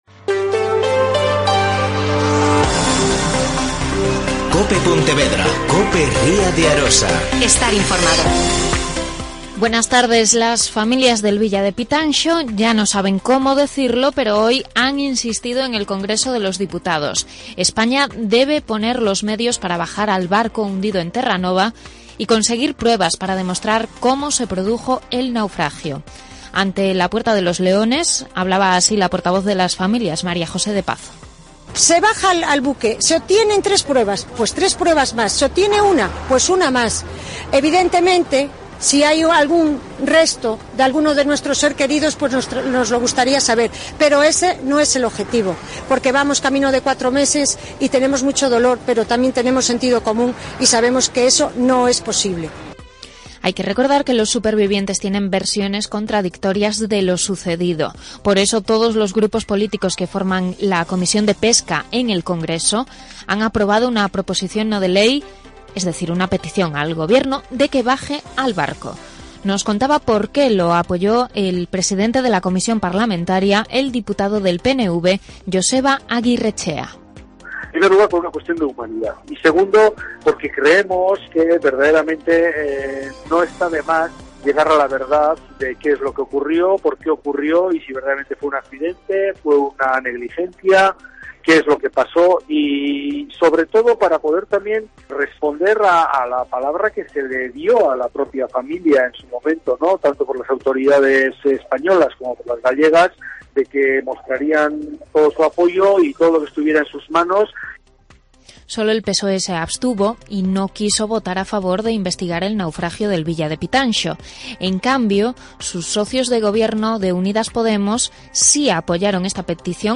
En declaraciones a COPE Pontevedra, diputados de PNV, Galicia en Común y PPdeG han argumentado por qué se debe intentar aclarar si hubo negligencias en el hundimiento de Terranova